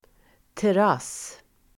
Uttal: [tär'as:]